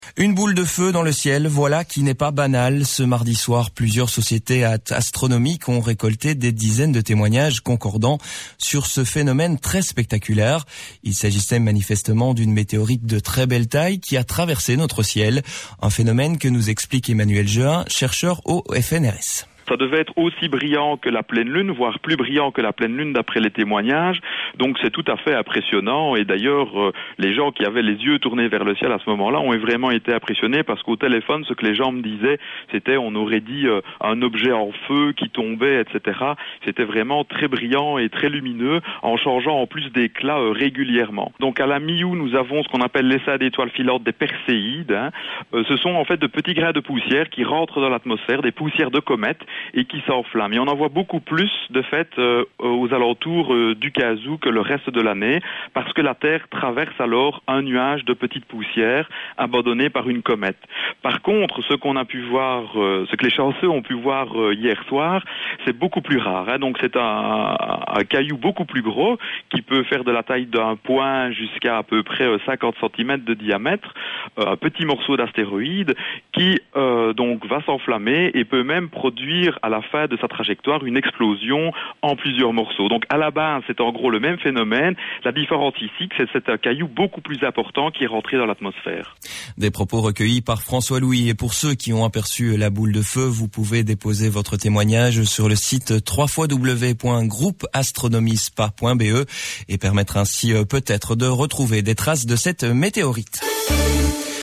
Voici, l’extrait du Journal Parlé de 7h30 du jeudi 15 octobre sur VivaCité Liège.